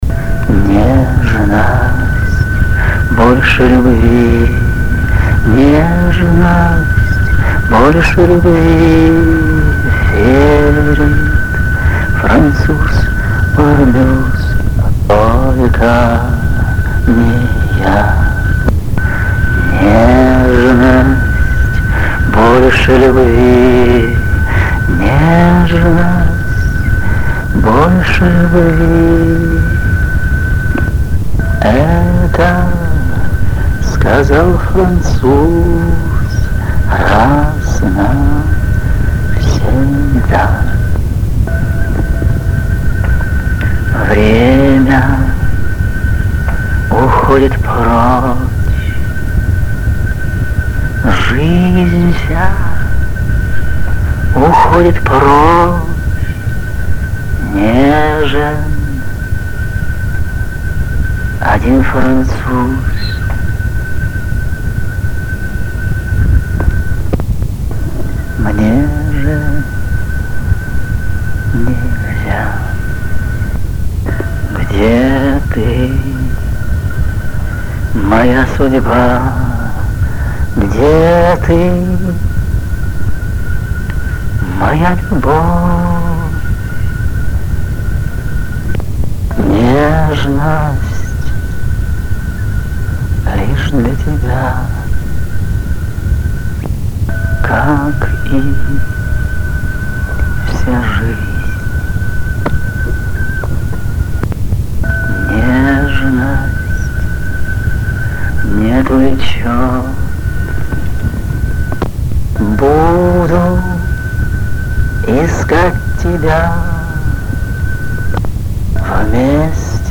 песня 6 Аристианская Песня, гитара.mp3
Рубрика: Поезія, Авторська пісня